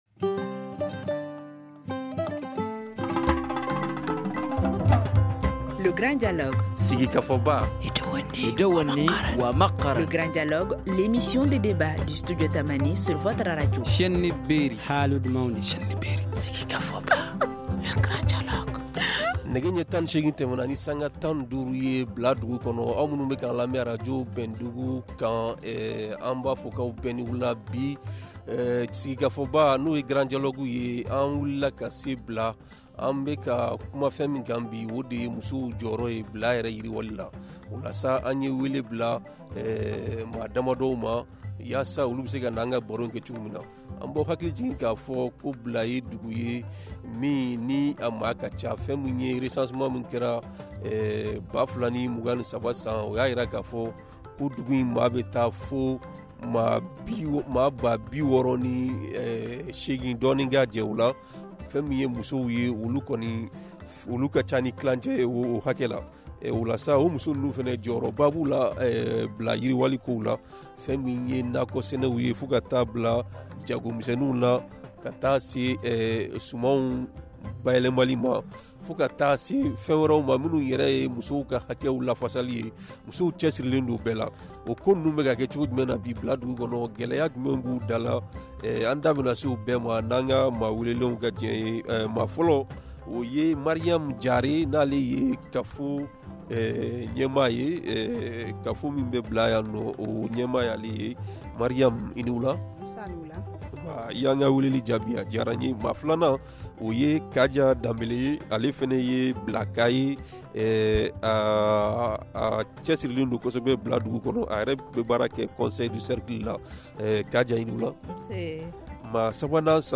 Nous en parlons avec nos invités :